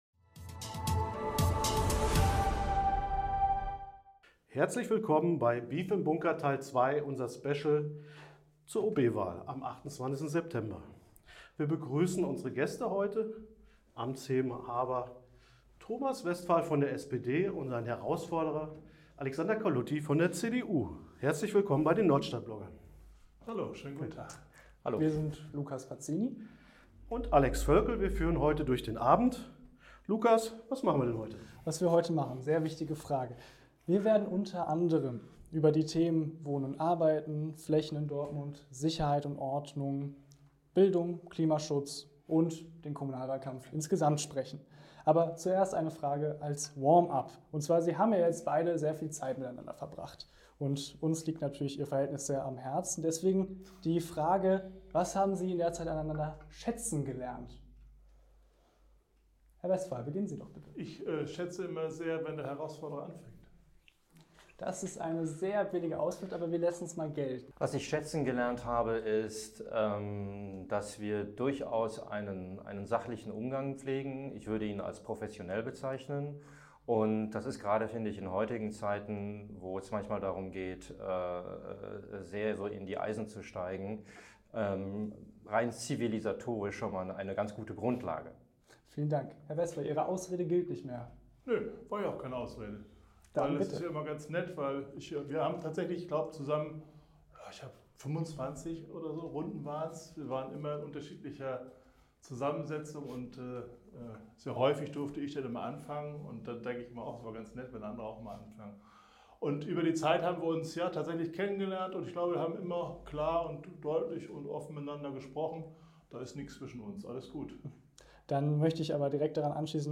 Wir haben in unserer Spezialausgabe des Systemfehler-Podcasts „Beef im Bunker“ mit den beiden über die drängensten Fragen für die Dortmunderinnen und Dortmunder diskutiert.